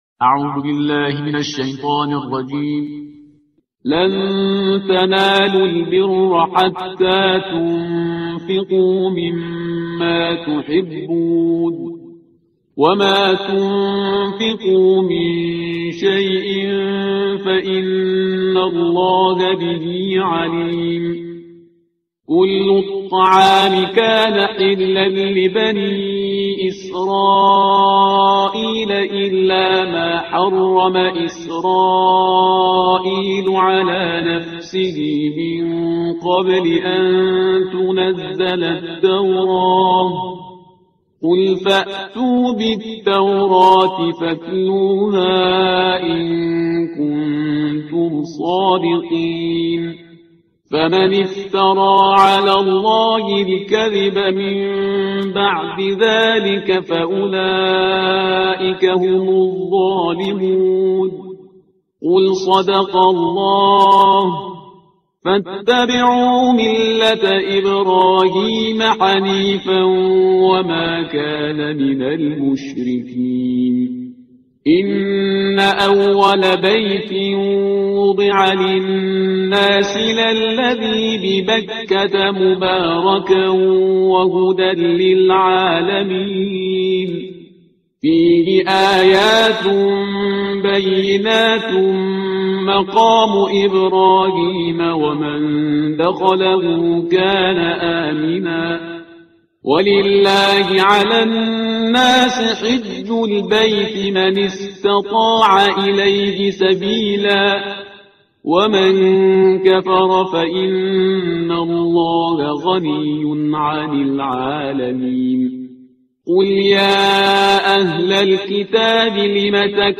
ترتیل صفحه 62 قرآن با صدای شهریار پرهیزگار